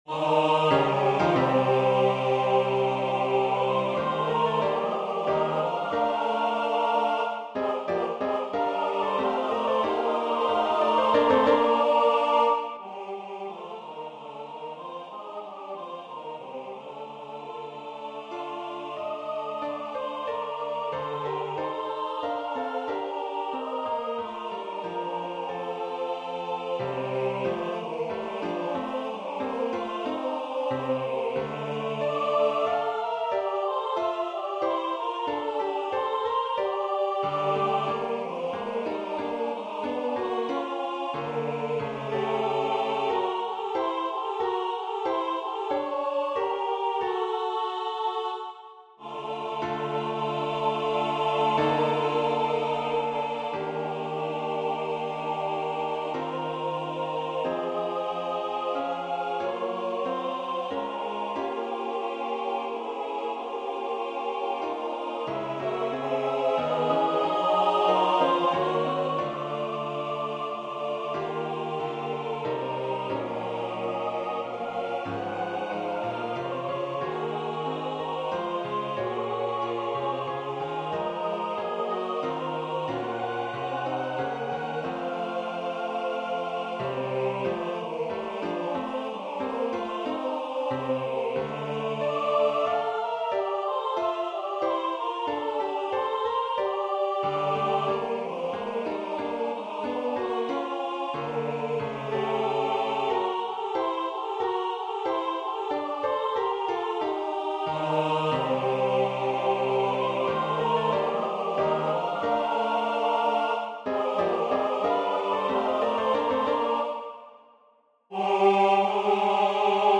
SATB chorus, piano